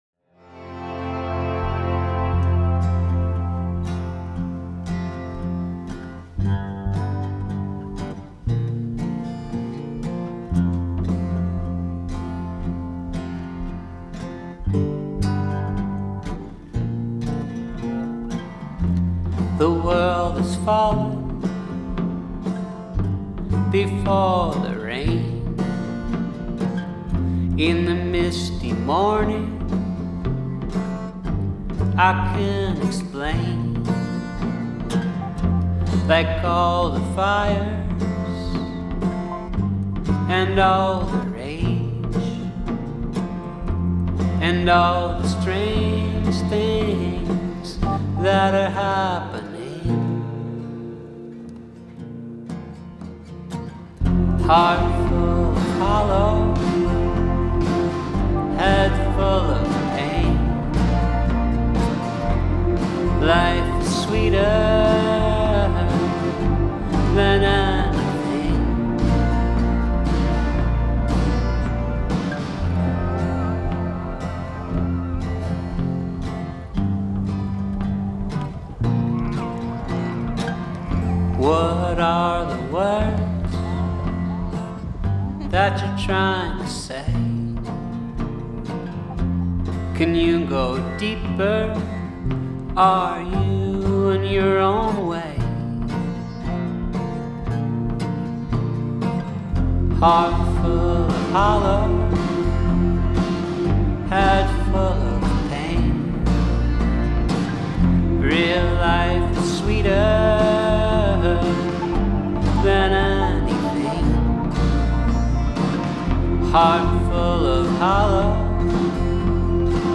21st Century Indie Folk Rock